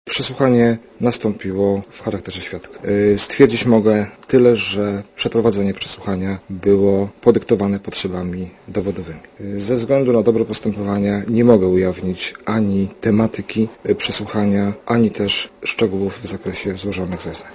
Komentarz audio